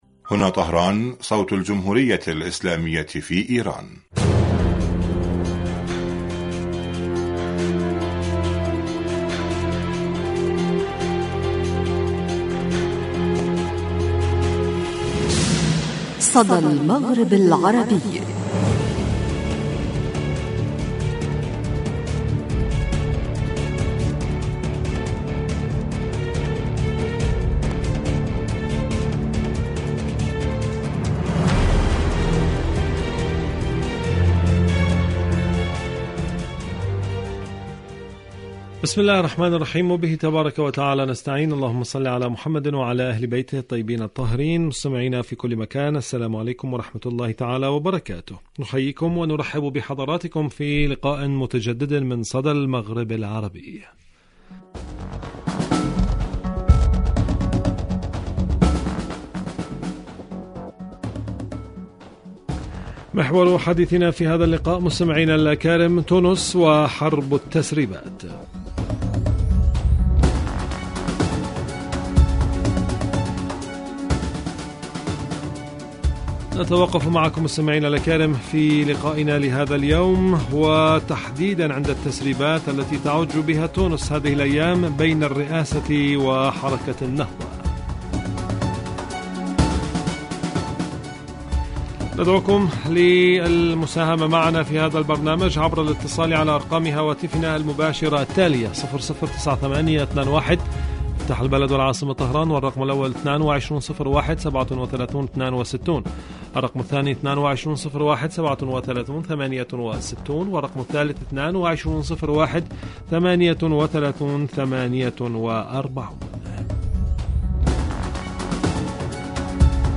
صدى المغرب العربي برامج إذاعة طهران العربية برنامج صدى المغرب العربي تونس الحكومة التونسية حرب التسريبات شاركوا هذا الخبر مع أصدقائكم ذات صلة الشعب المغربي يواصل رفضه للتطبيع إيران والجزائر..